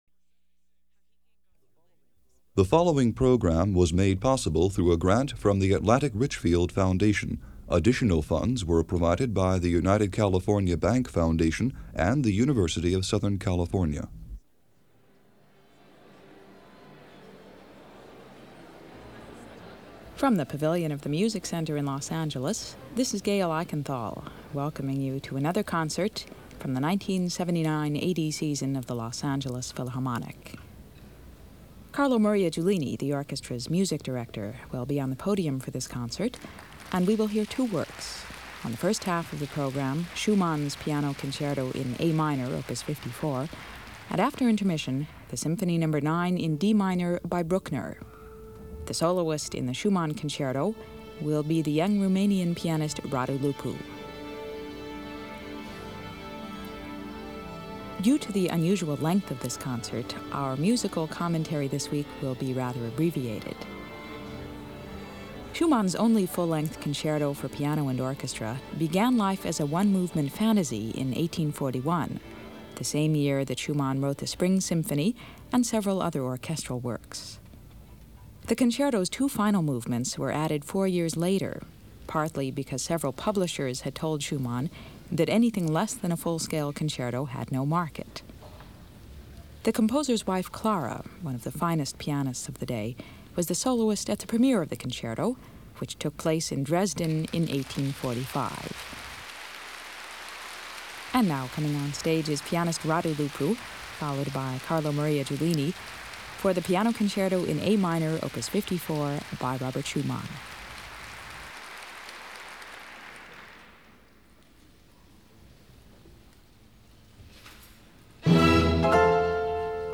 Click on the link here for Audio Player – Los Angeles Philharmonic – Carlo Maria Giulini – Radu Lupu, piano – March 8, 1980 – Part 1
Another classic concert this week. This time it’s The Los Angeles Philharmonic, conducted by Music Director Carlo Maria Giulini and featuring the legendary Pianist Radu Lupu in the Schumann Piano Concerto.
It was recorded by Radio station KUSC and distributed by NPR (back in the days when NPR was doing those sorts of broadcasts) on March 8, 1980.